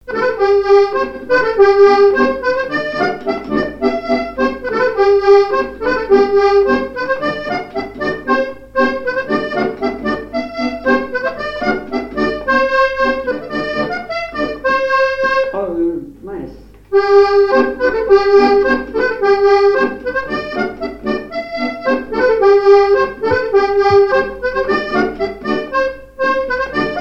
Mémoires et Patrimoines vivants - RaddO est une base de données d'archives iconographiques et sonores.
Saint-Gervais
branle
Chants brefs - A danser
airs de danse à l'accordéon diatonique